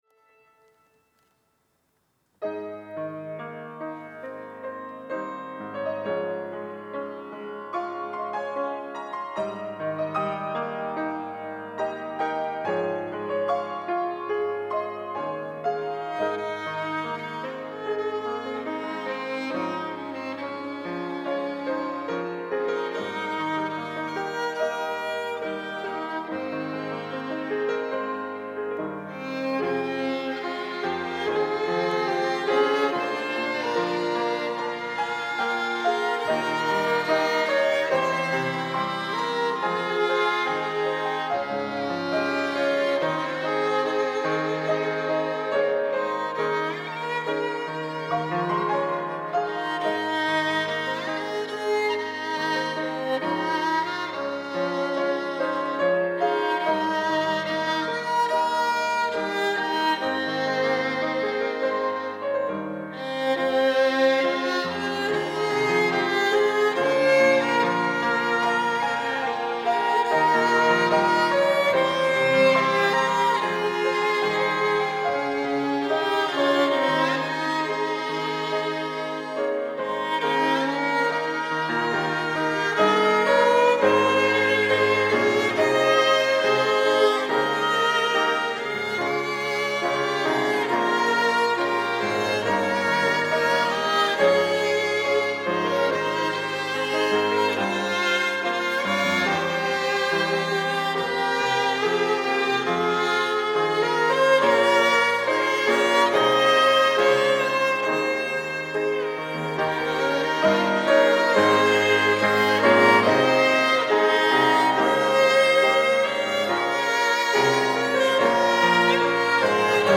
특송과 특주 - 여호와는 나의 목자